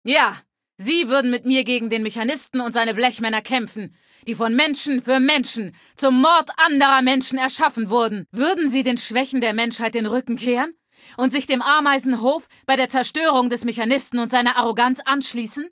Datei:Femaleadult01default ms02 ms02superheroexplain2 0003c8cc.ogg